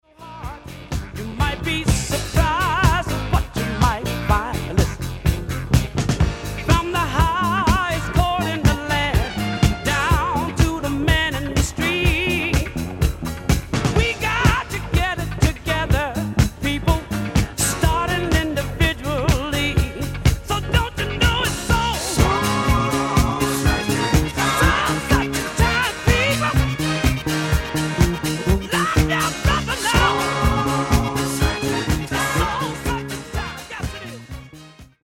Genere:   Disco Funky